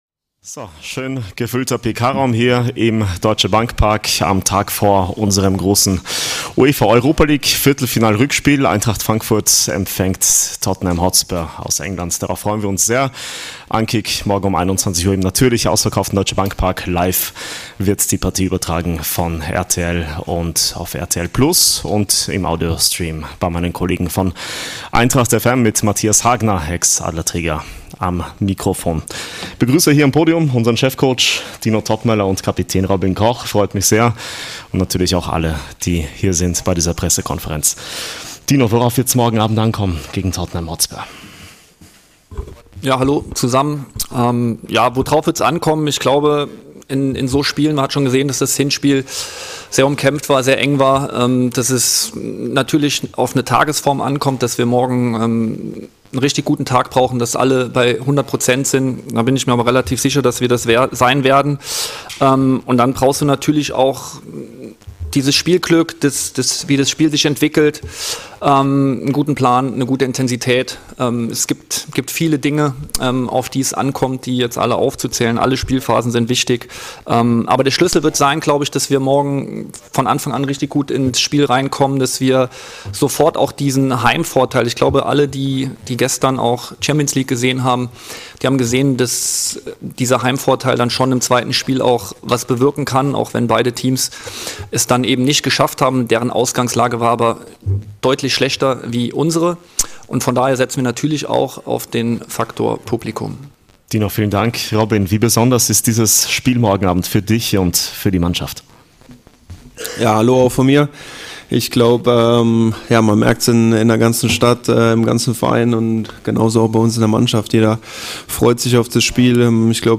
Der zweite Podcast von Eintracht Frankfurt greift die alltäglichen Geschehnisse in der Eintracht-Welt auf. Egal, ob Pressekonferenzen vor und nach den Partien, aktuelle Stimmen und Interviews, Vorstellungen neuer Spieler und mehr!